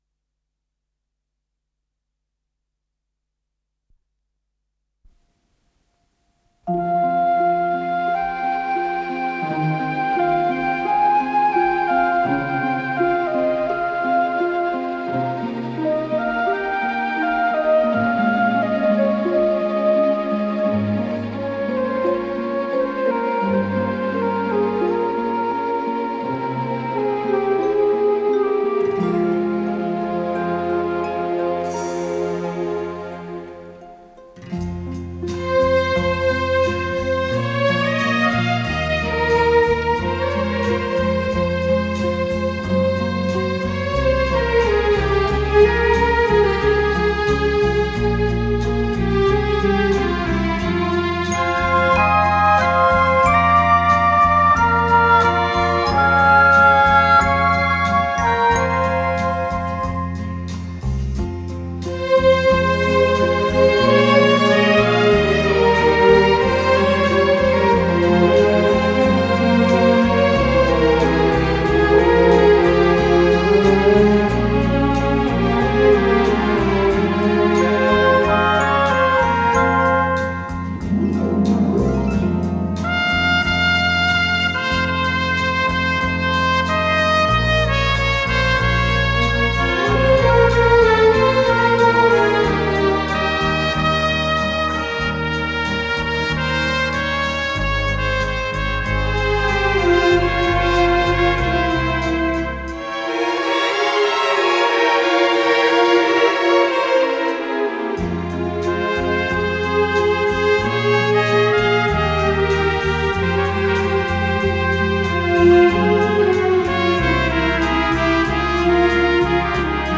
с профессиональной магнитной ленты
фа минор
Скорость ленты38 см/с